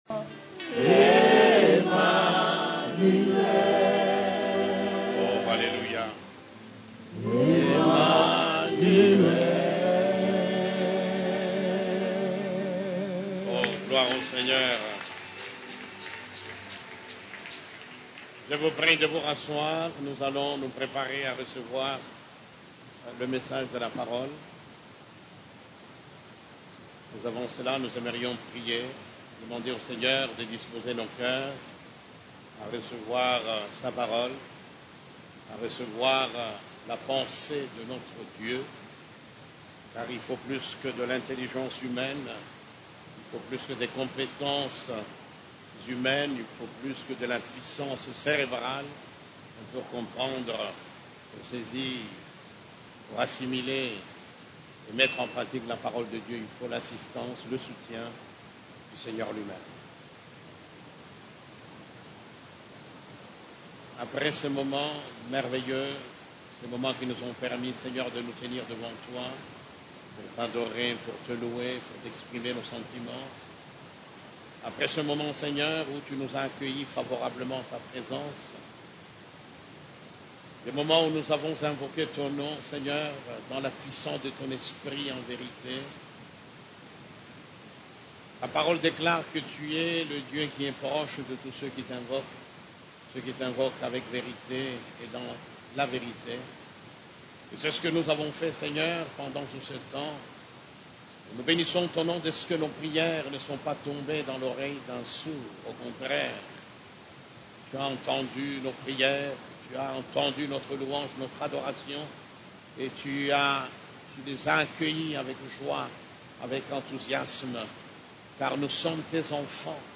CEF la Borne, Culte du Dimanche, L'entrée du lieu secret 4